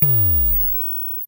Index of /90_sSampleCDs/300 Drum Machines/Klone Dual-Percussion-Synthesiser/KLONE FILT NW8